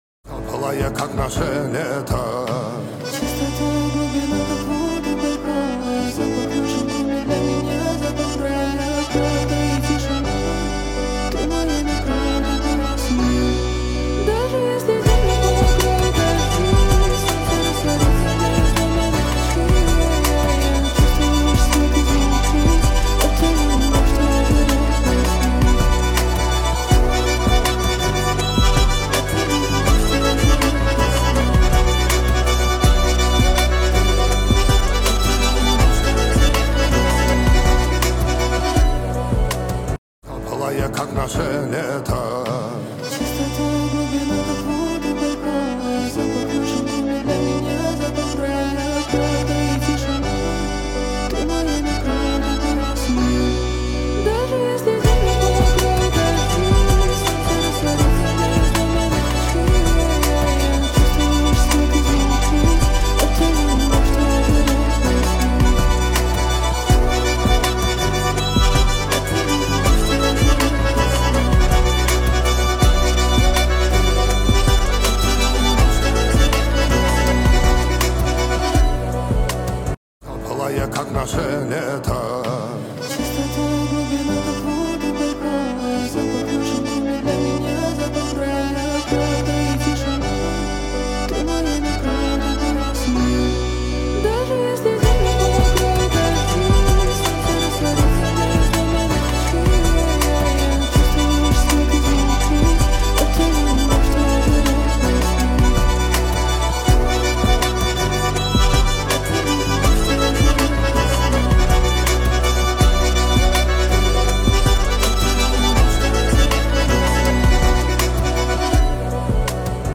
Аккордеон